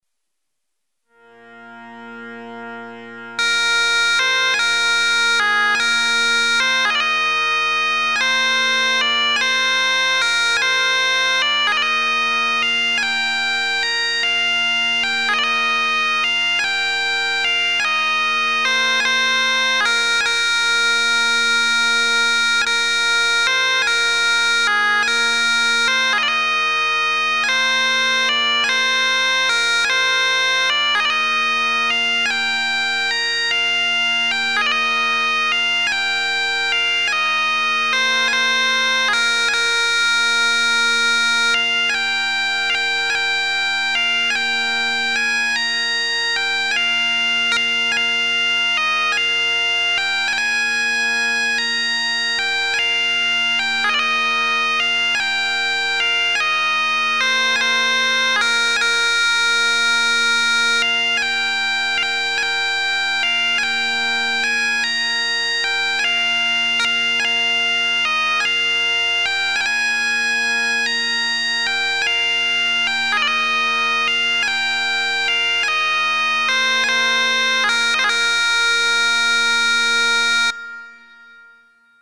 Scots Wha Hae, bagpipes solo (1.3MB, .mp3, 1m22s)
Bagpipes - Scots Wha Hae.mp3